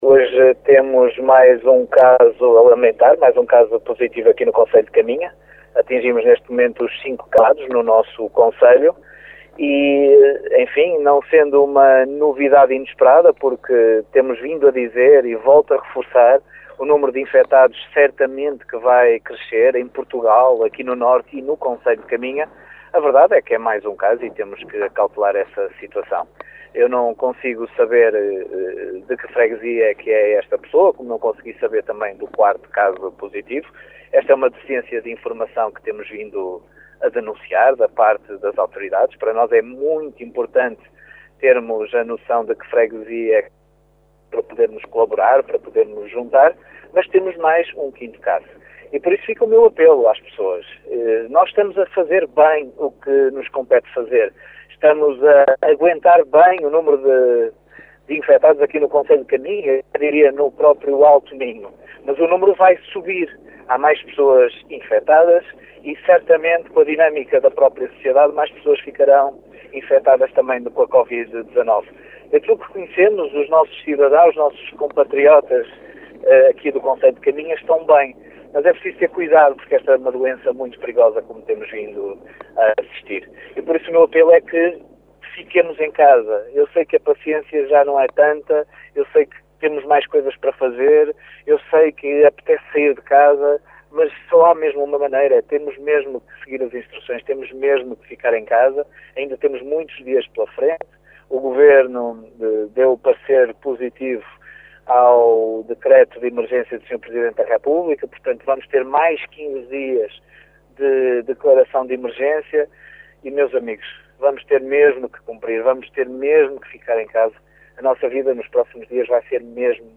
Miguel Alves a apelar mais uma vez à população de Caminha para que permaneça em casa.